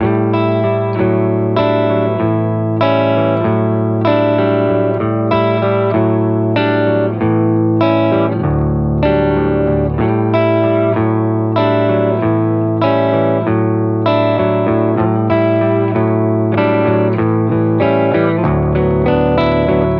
HeavensBells.wav